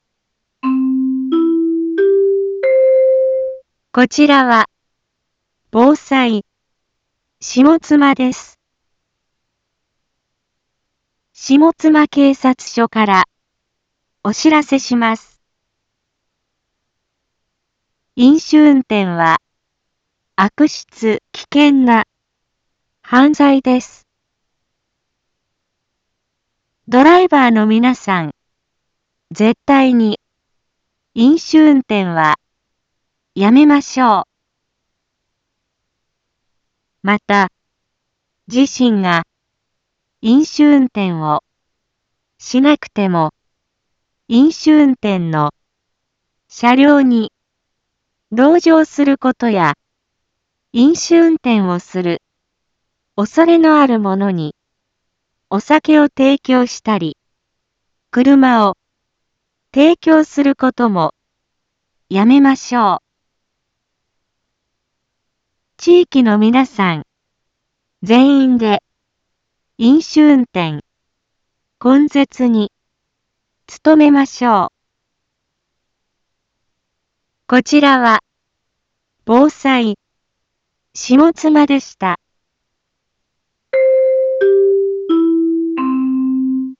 一般放送情報
Back Home 一般放送情報 音声放送 再生 一般放送情報 登録日時：2023-12-08 17:31:30 タイトル：飲酒運転根絶のための県下一斉広報日につい インフォメーション：こちらは、防災、下妻です。